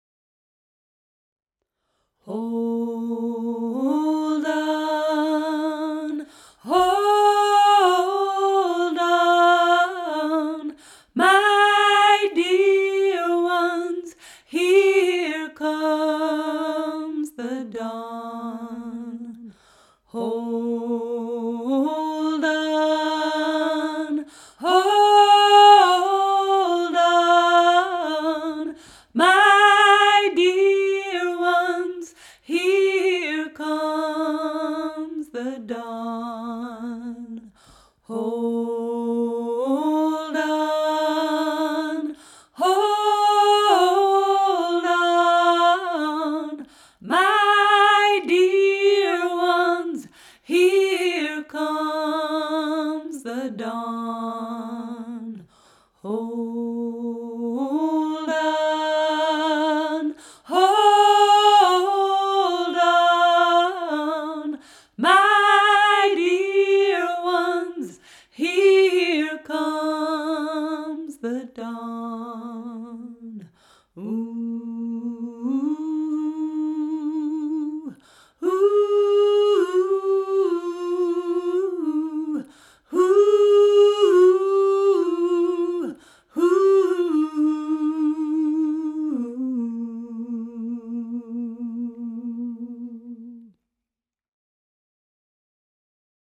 Tune: